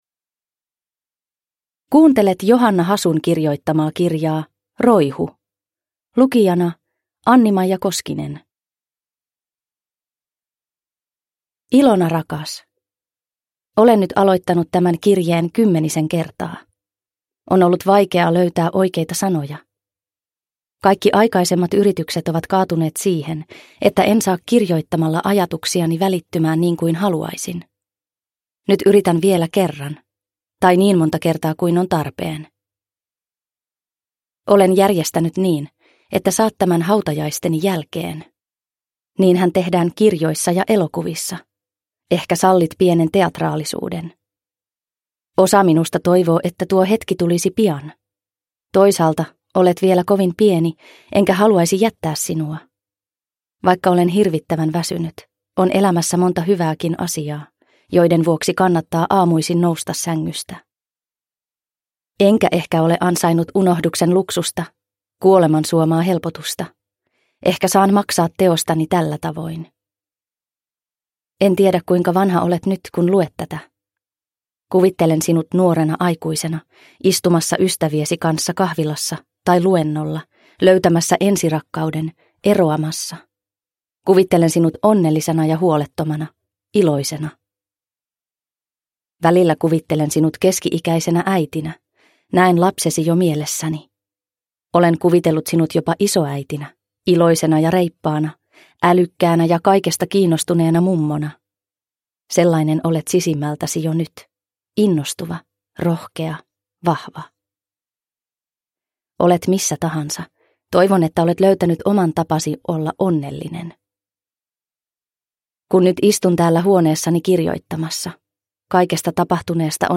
Roihu – Ljudbok – Laddas ner